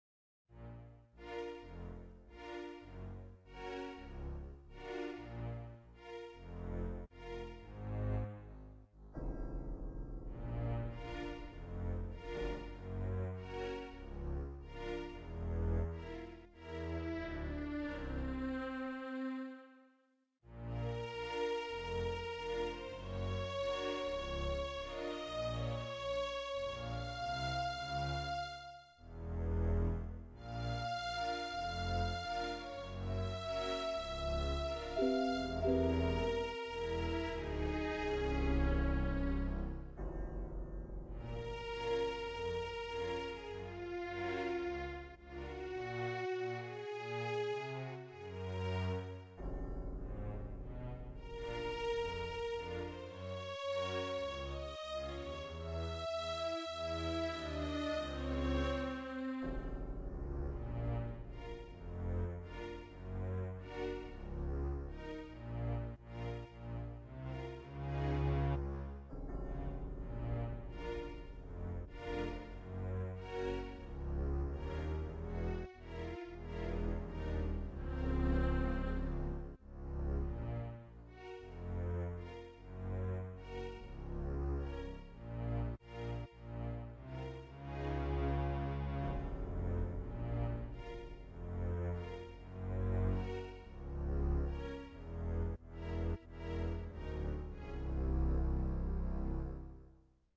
BGM Music short tune